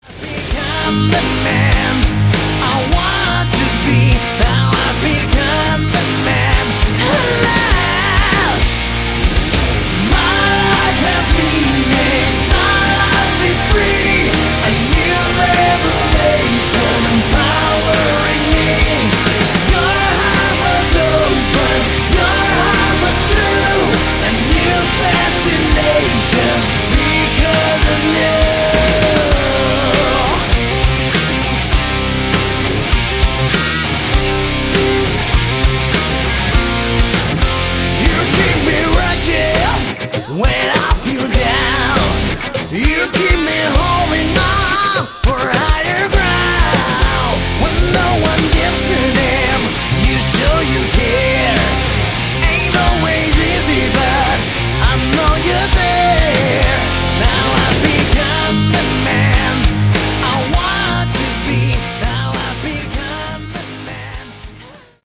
Category: Hard Rock
vocals
guitar
bass
drums